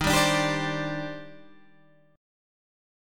D#M13 chord